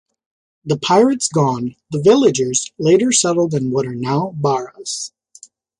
Pronúnciase como (IPA) /ˈpaɪɹəts/